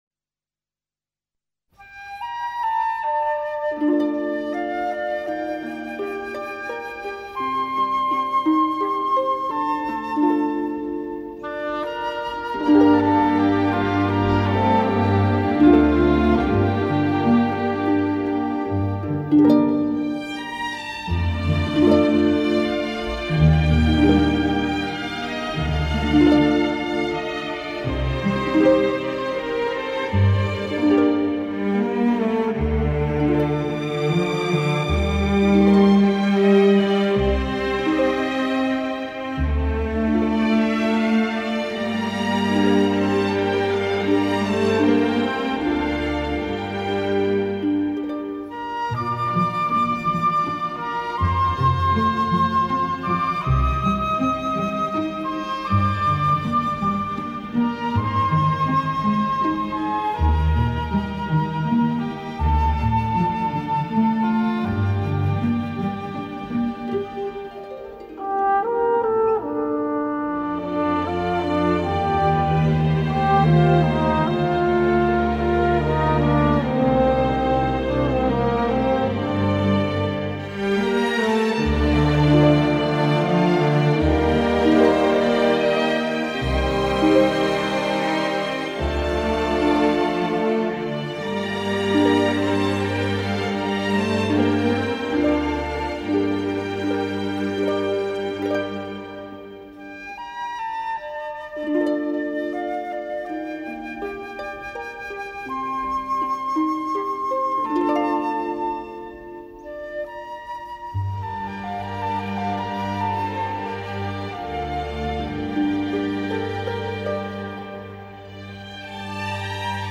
При оформлении подарочных коробочек можно включить музыку.